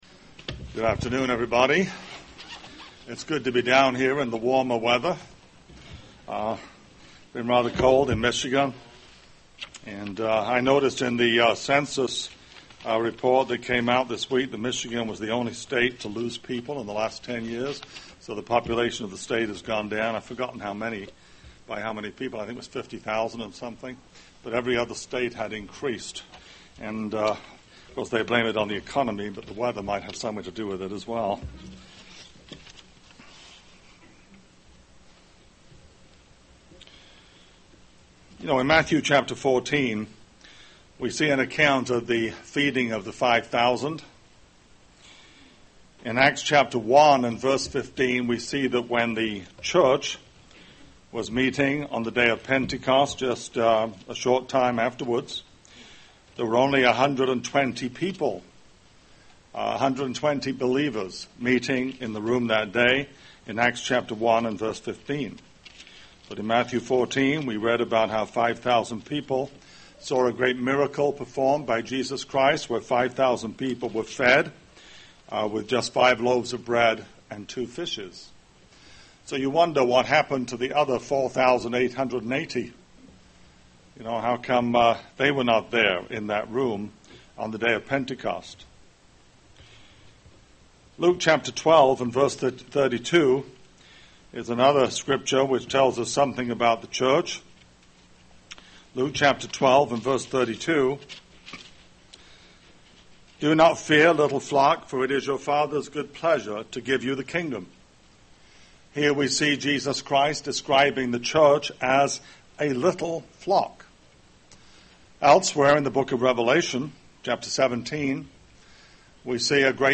UCG Sermon Studying the bible?
Given in Cincinnati East, OH